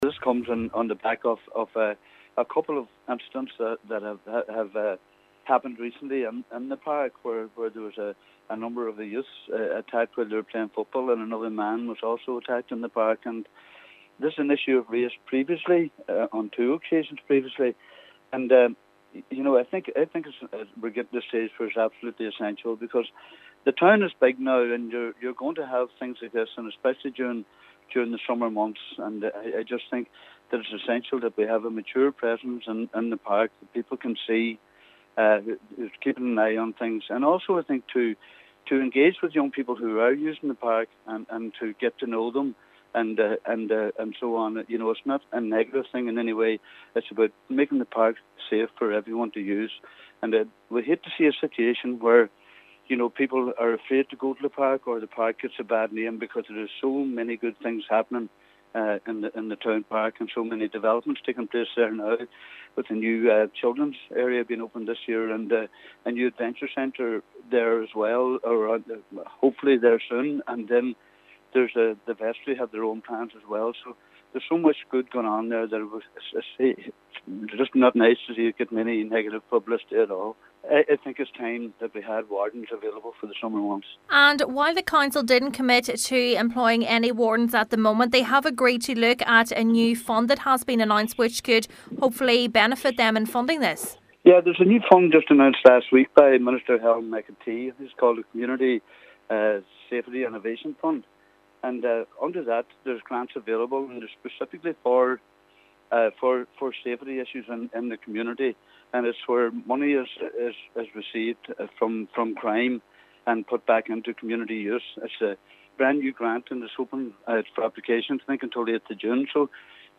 Councillor Jimmy Kavanagh the provision of wardens would provide a greater sense of security for park users: